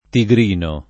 vai all'elenco alfabetico delle voci ingrandisci il carattere 100% rimpicciolisci il carattere stampa invia tramite posta elettronica codividi su Facebook tigrino [ ti g r & no ] (meno com. tigrigno [ ti g r & n’n’o ]) etn.